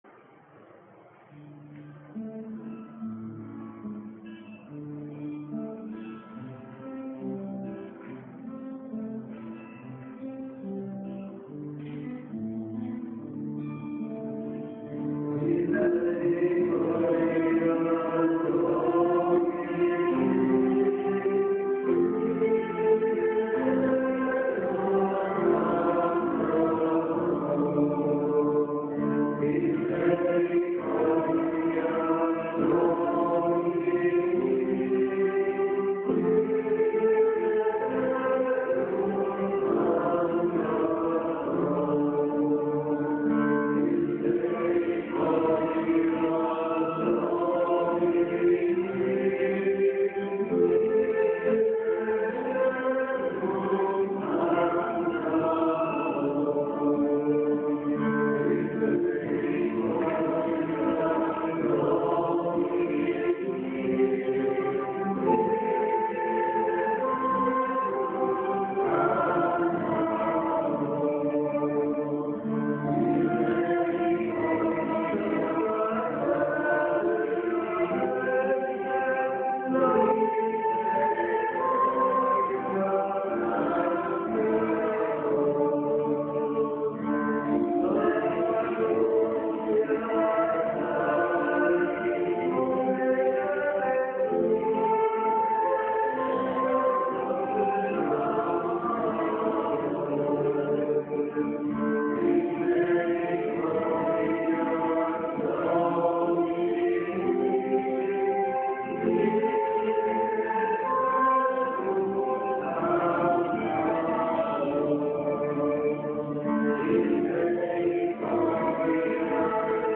Pregària de Taizé
Església de Santa Anna - Diumenge 27 d'octubre de 2013